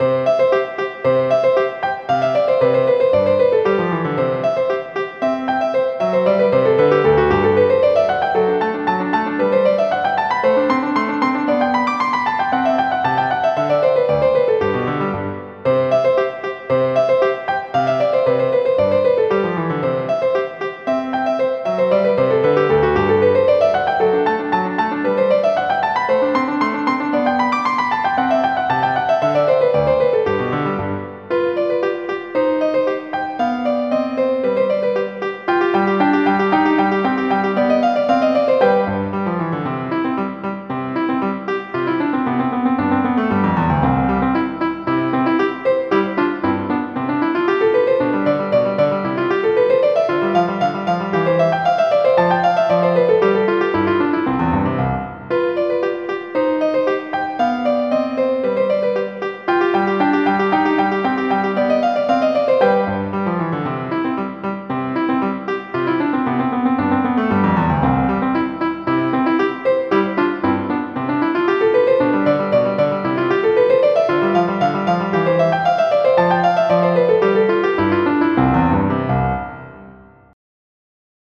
MIDI Music File
sonatina.mp3